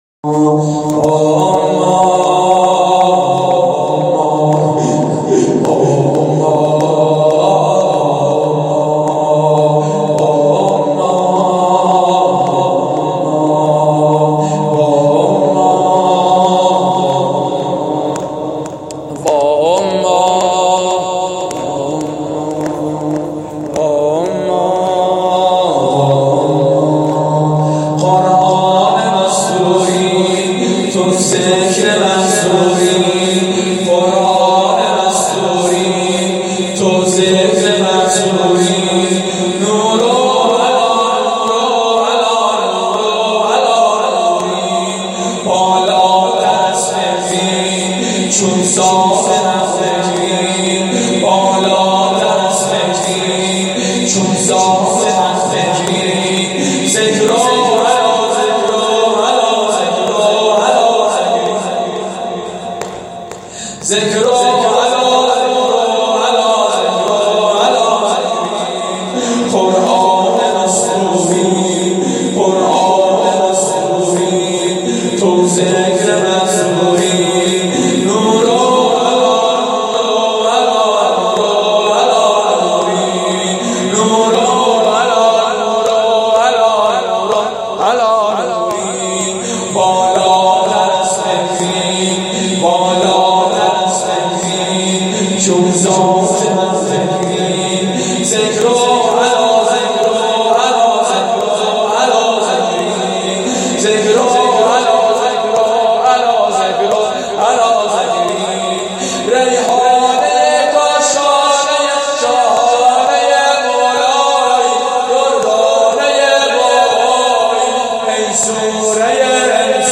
زمینه قرآن مستوری تو ذکر محصوری فاطمیه اول مسجد جواد الائمه (ع)